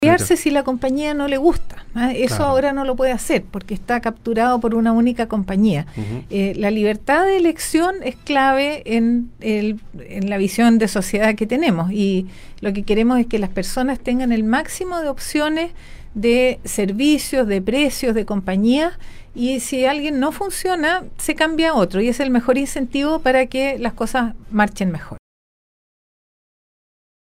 La Ministra de Transporte y Telecomunicaciones, Gloria Hutt, estuvo de visita en la capital regional donde cumplió con una apretada agenda, la cual permitió visitar las instalaciones de Nostálgica, donde se refirió al proyecto que ampliará la cobertura de telefonía e Internet en la región de Atacama.